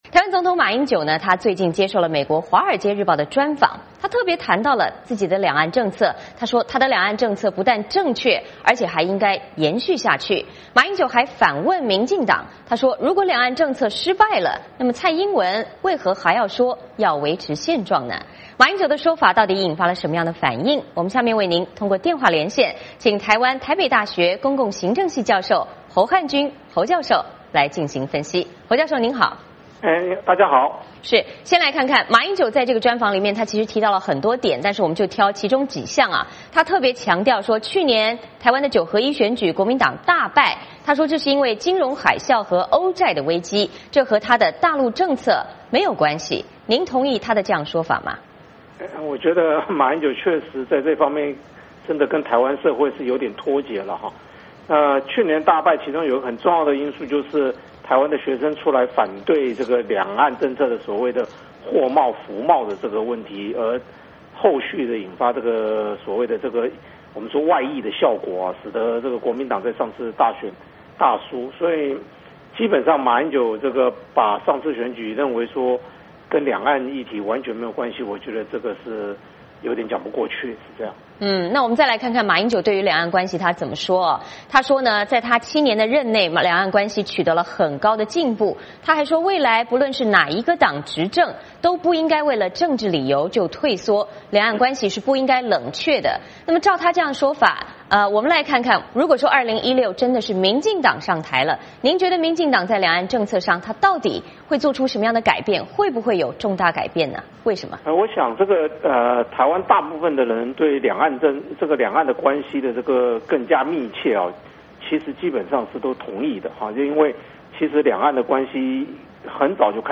我们通过电话连线